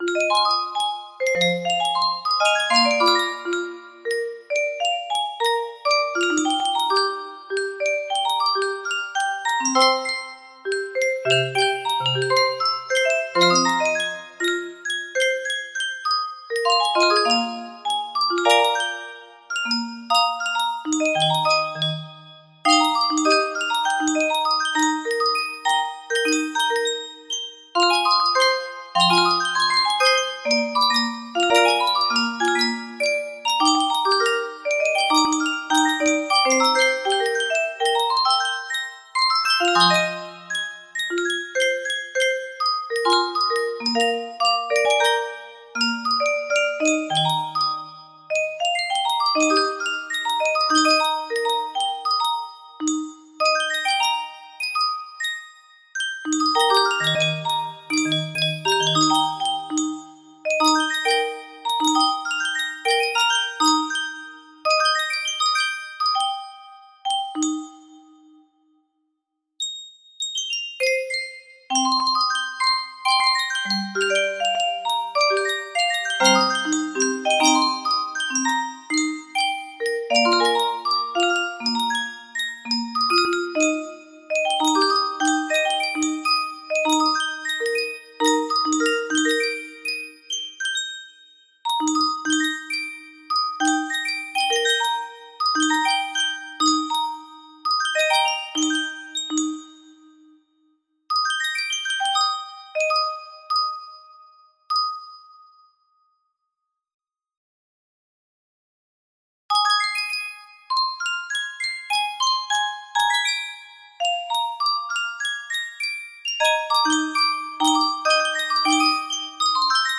Threads Of Gold 9 music box melody
Full range 60